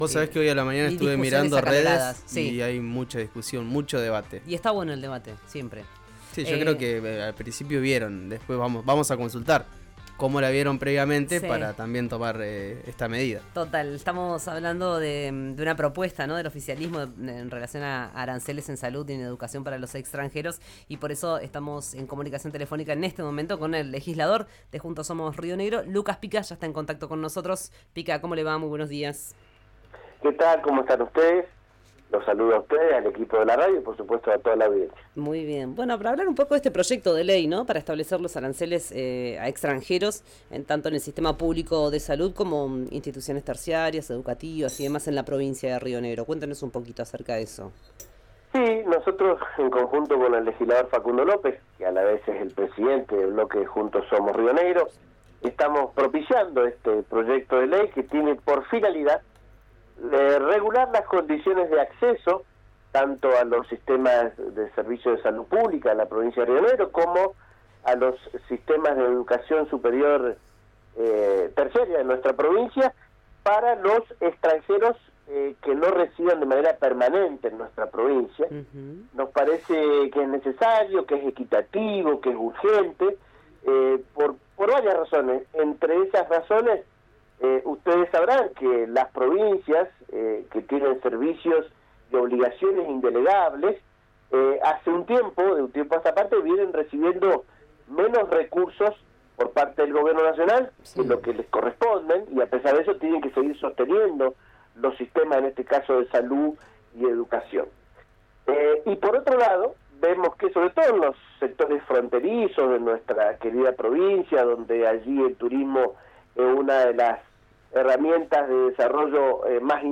Escuchá al legislador Lucas Pica en el aire de RÍO NEGRO RADIO